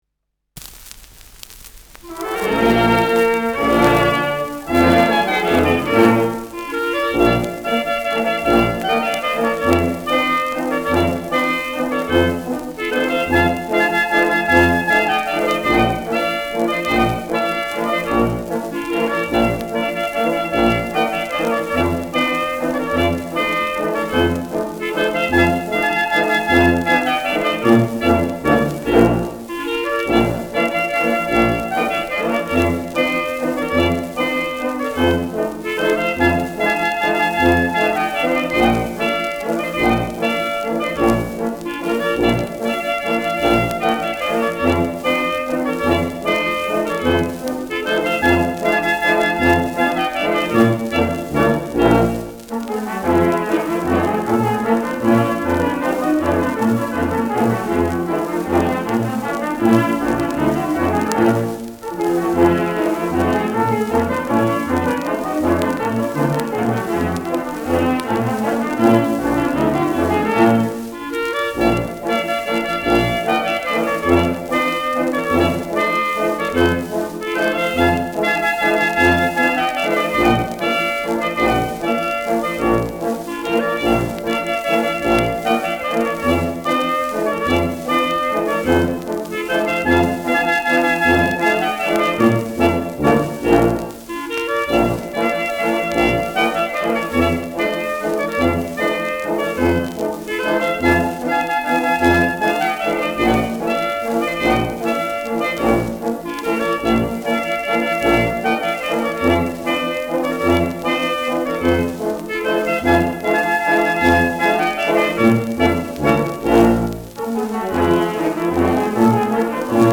Schellackplatte
leichtes Rauschen : leichtes Knistern : leichtes Leiern : Nadelgeräusch
[München] (Aufnahmeort)